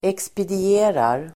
Uttal: [ekspedi'e:rar]